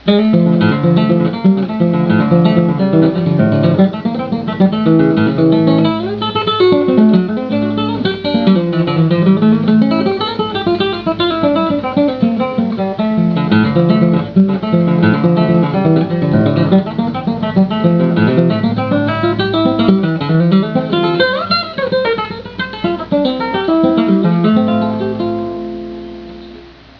Tuning: EADGBE Key: F Major Sample:
Comments: This is one of the greatest arpeggio studies ever written.